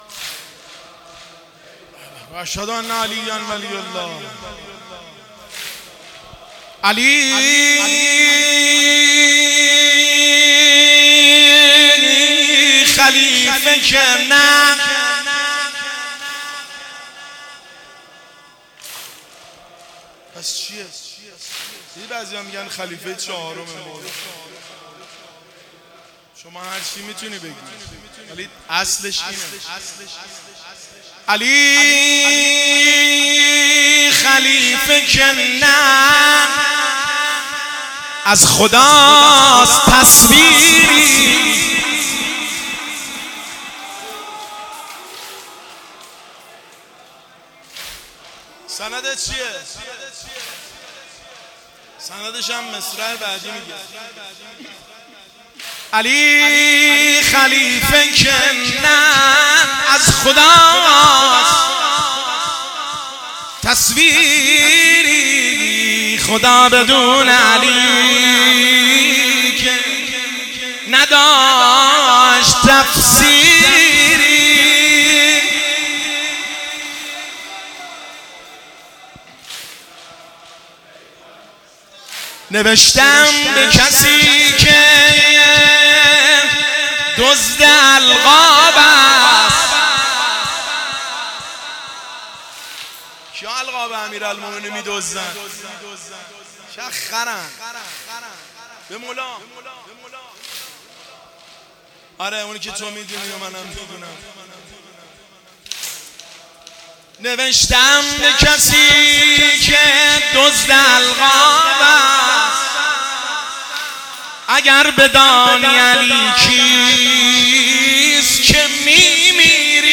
هیئت بین الحرمین طهران